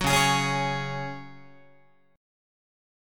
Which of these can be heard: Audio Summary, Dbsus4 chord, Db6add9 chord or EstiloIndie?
Dbsus4 chord